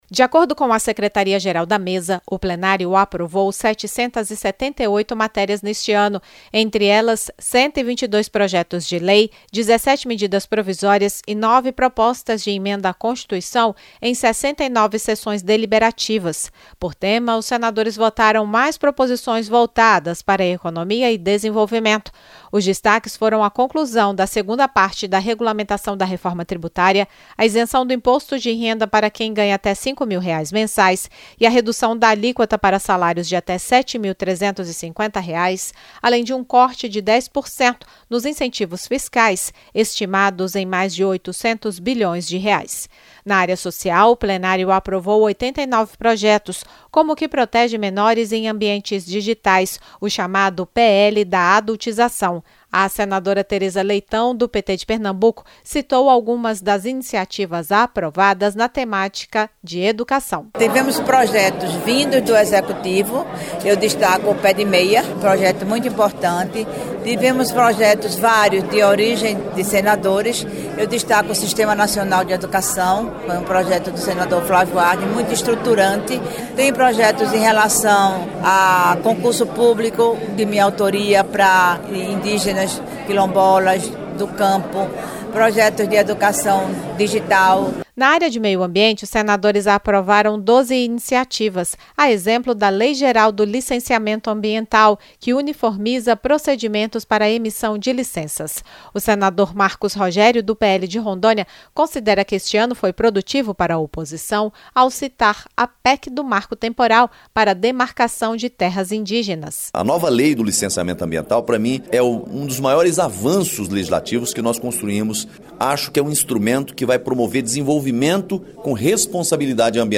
Já o senador Marcos Rogério (PL-RO) apontou como relevante a votação de propostas na área ambiental, entre elas, a definição da promulgação da Constituição de 1988 como marco temporal para demarcação de terras indígenas (PEC 48/2023).